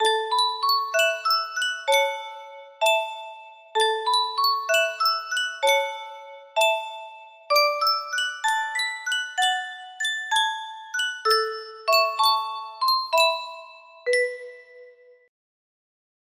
Yunsheng Music Box - Frankie and Johnny 5967 music box melody
Full range 60